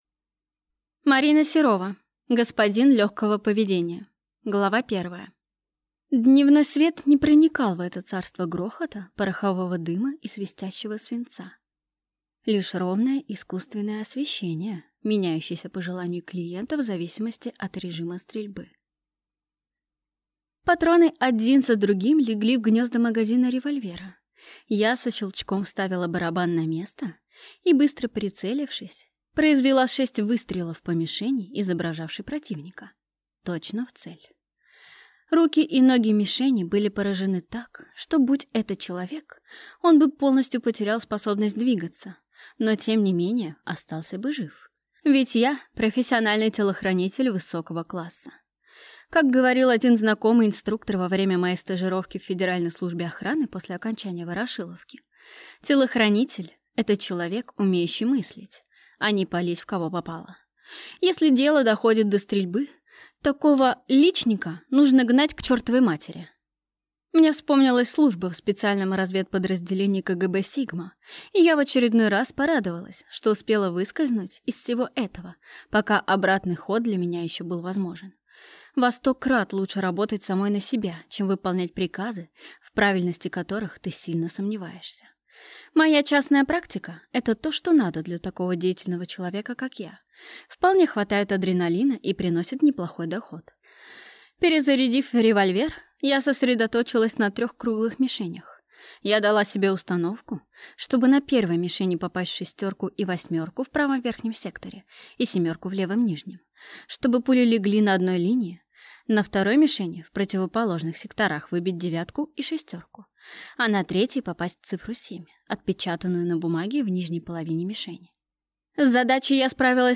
Аудиокнига Господин легкого поведения | Библиотека аудиокниг